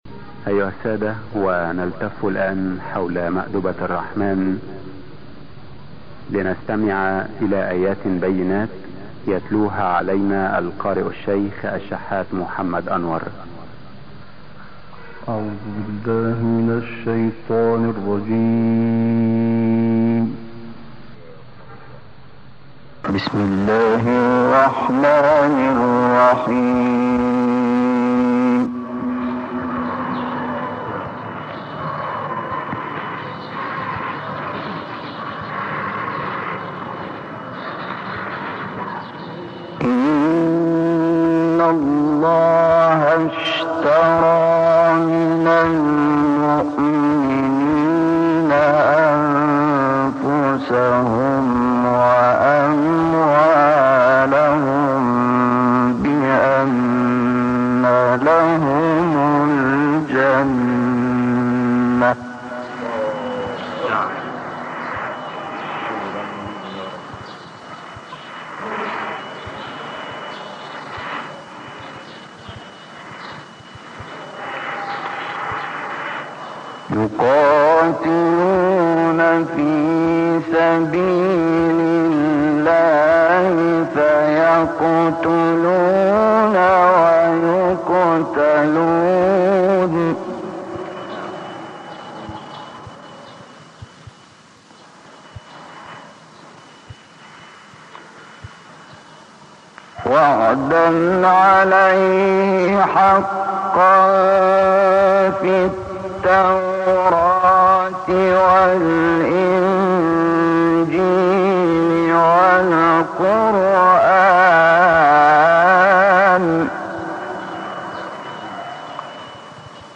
تهران - الکوثر : تلاوت آیات 111 تا 121 سوره توبه توسط شیخ شحات محمد انور.